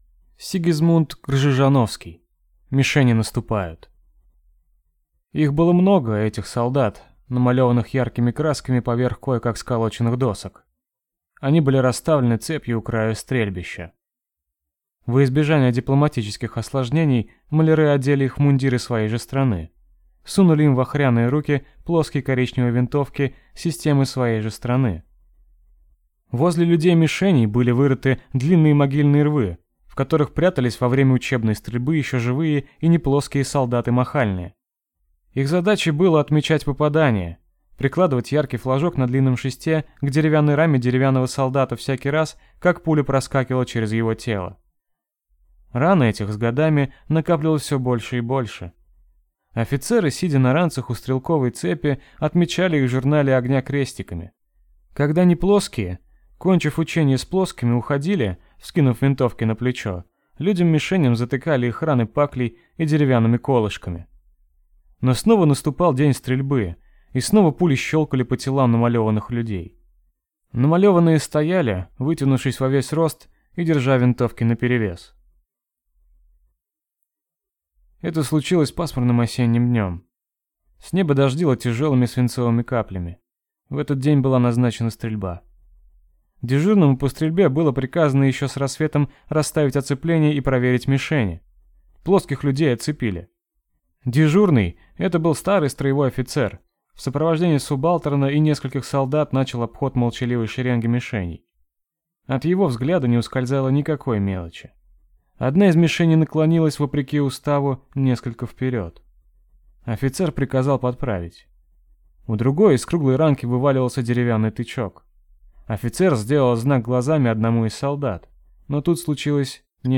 Аудиокнига Мишени наступают | Библиотека аудиокниг